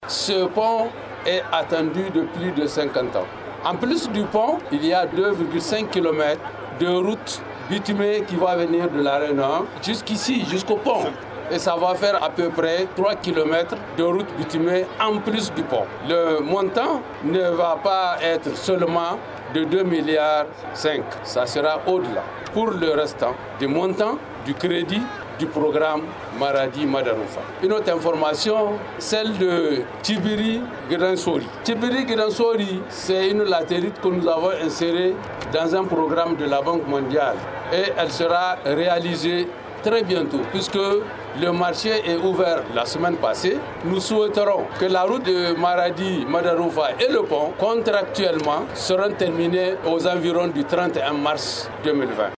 Interview du ministre de l’équipement Kadi Abdoulaye